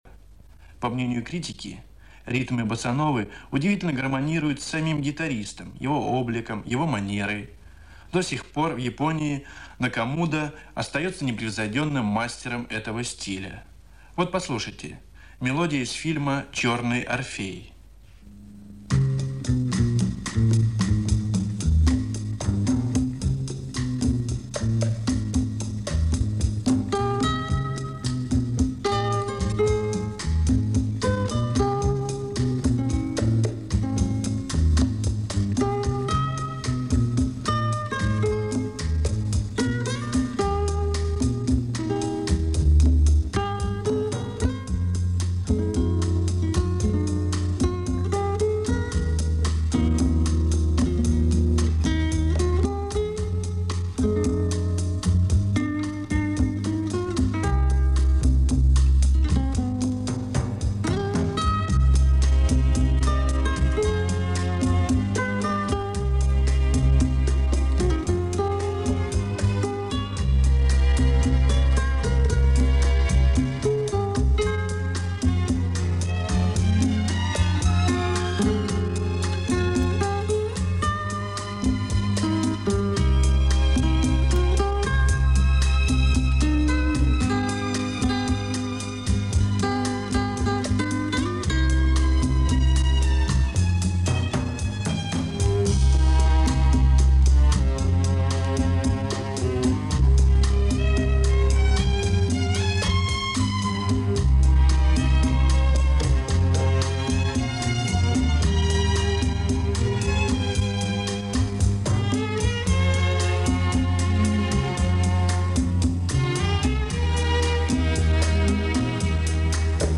запись с эфира